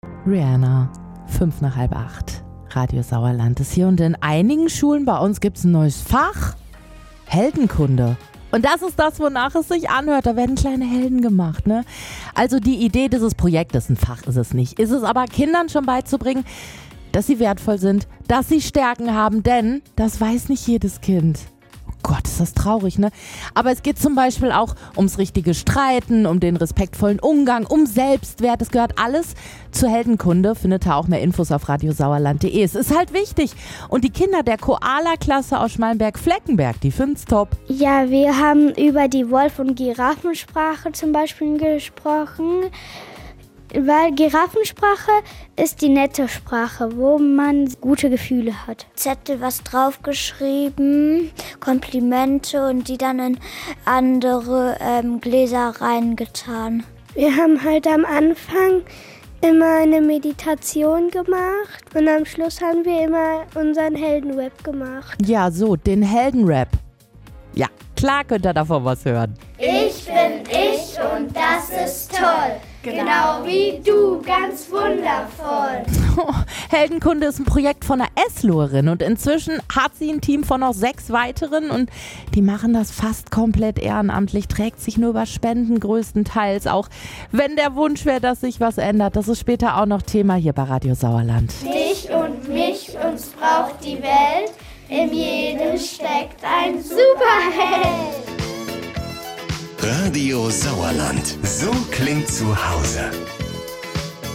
Radio SauerlandHeldenkunde - der Rap